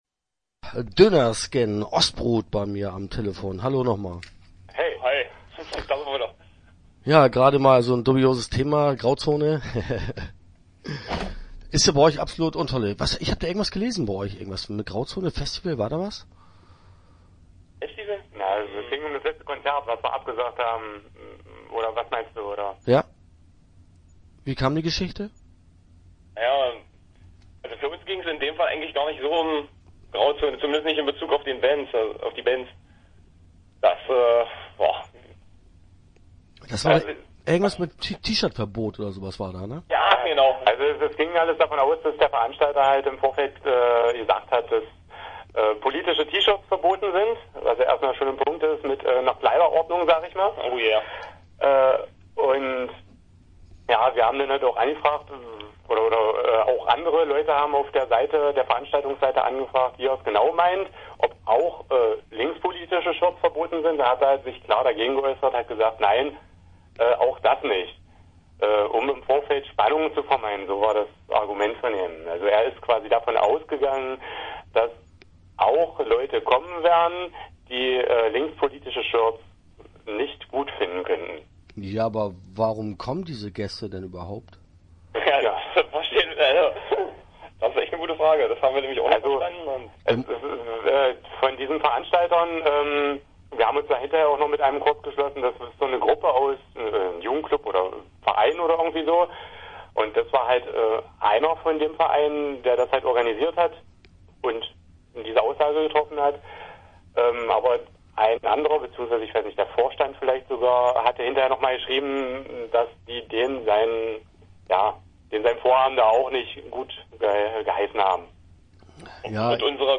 Interview Teil 1 (9:19)